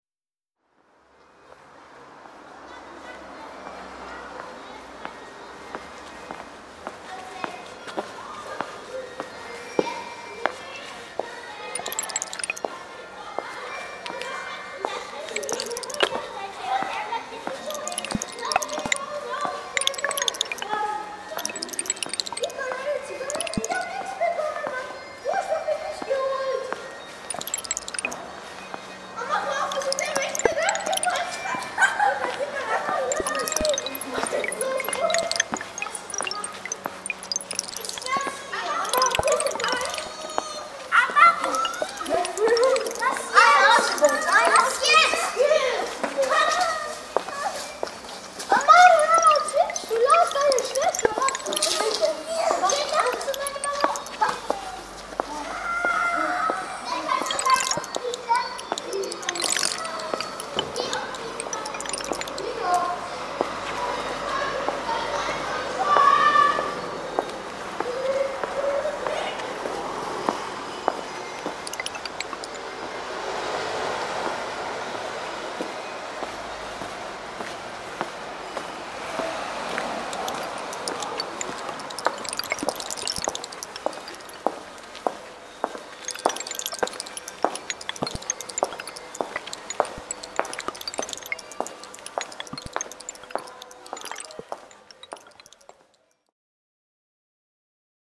acoustic scenery of the Schwaebisch Gmuend (2012)